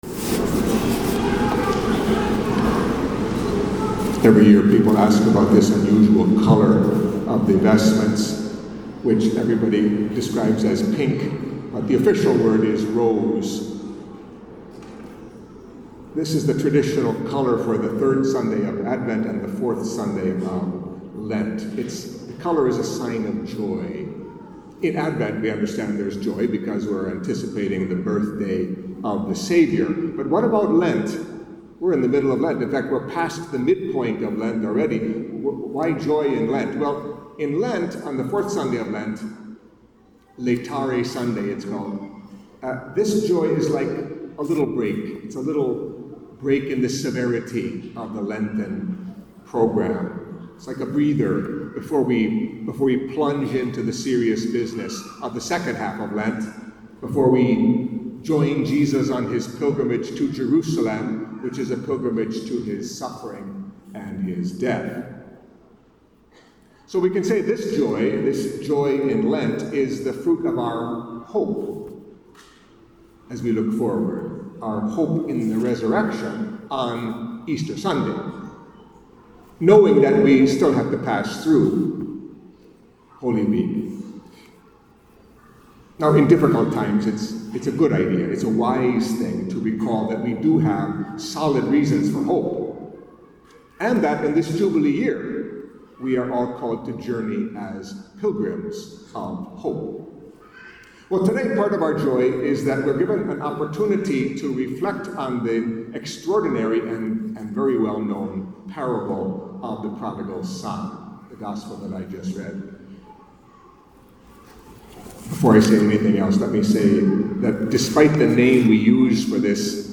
Catholic Mass homily for Fourth Sunday of Lent